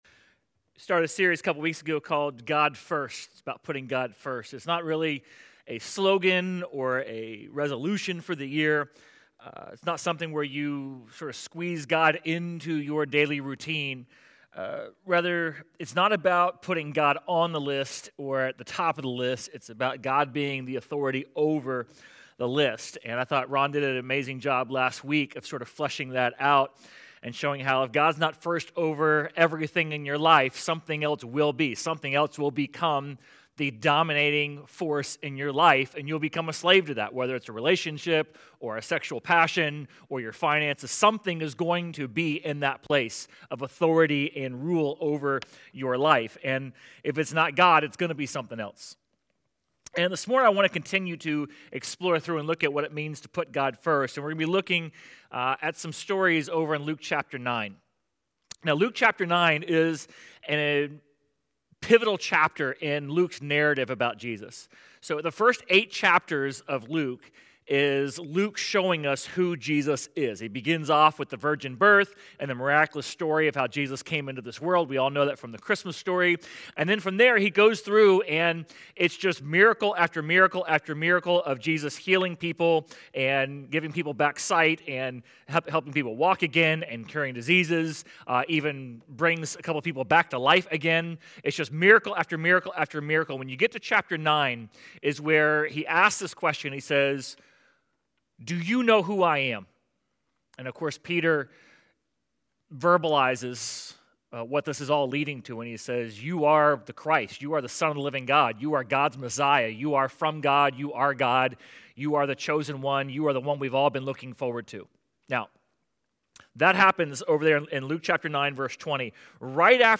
Sermon_2.1.26.mp3